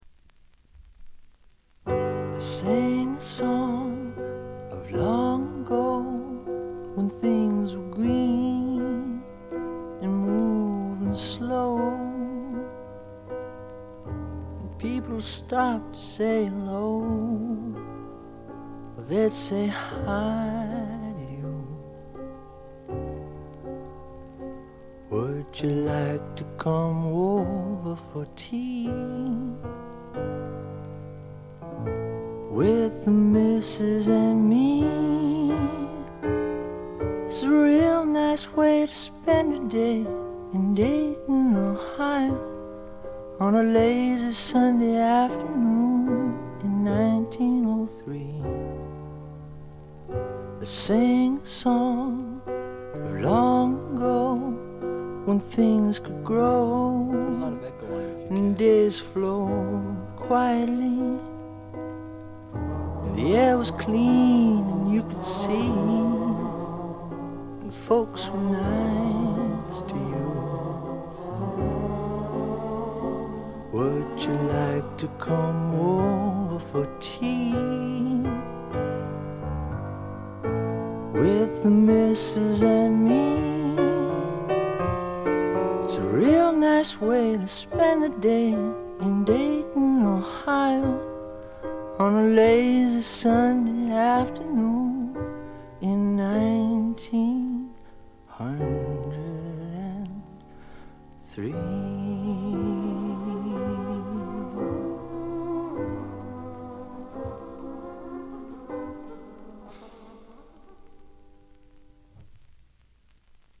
軽微なバックグラウンドノイズ程度。
試聴曲は現品からの取り込み音源です。